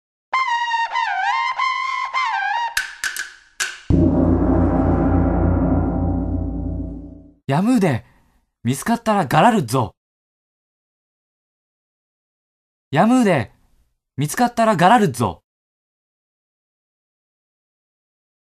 • 読み上げ